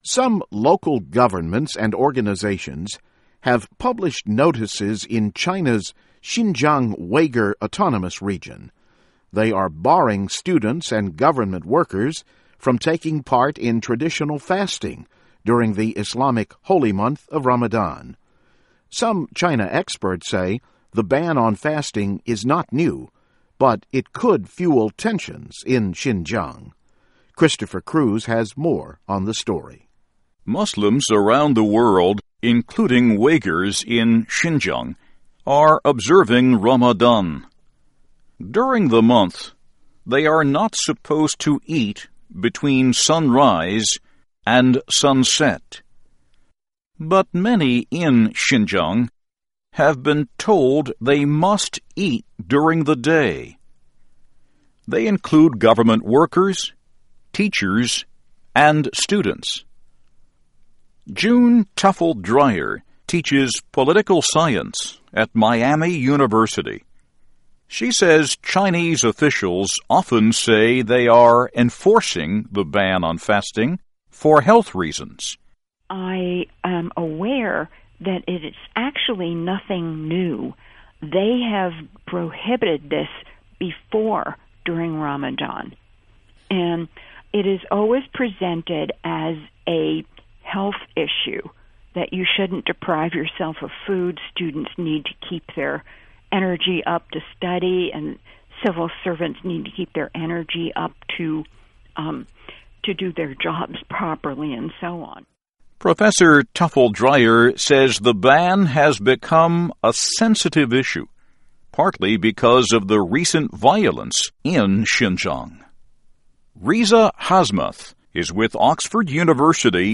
by VOA - Voice of America English News